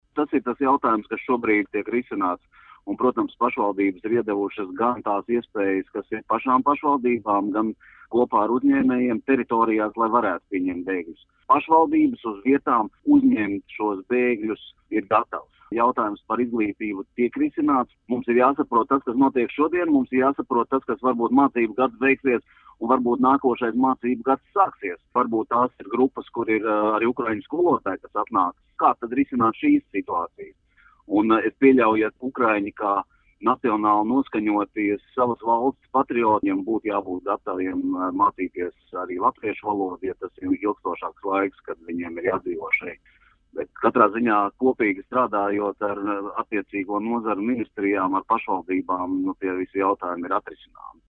Ne vien galvaspilsēta, bet arī daudzas citas pilsētas un novadi iesaistās bēgļu izmitināšanā un sadzīves iekārtošanā. Par to, cik gatavas šādam atbalstam ir Latvijas pašvaldības ,vaicājām Pašvaldību savienības priekšsēdim Gintam Kaminskim: